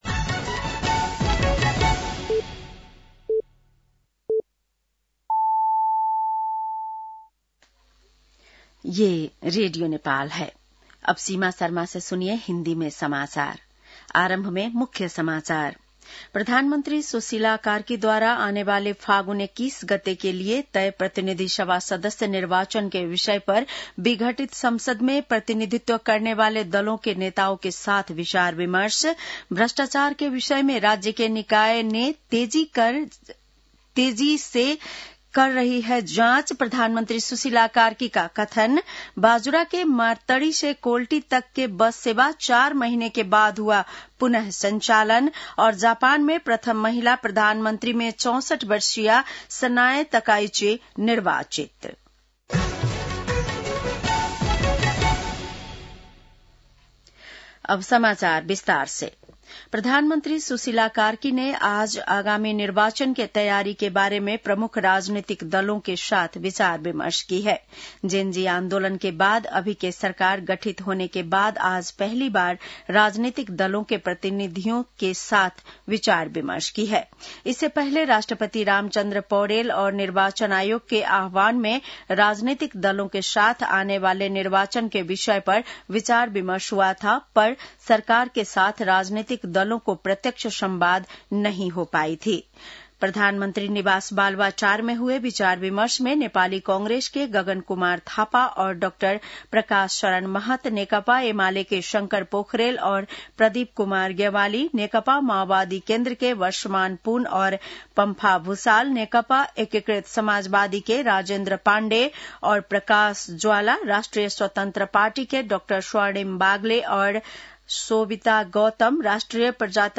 बेलुकी १० बजेको हिन्दी समाचार : ४ कार्तिक , २०८२
10-pm-hindi-news-7-04.mp3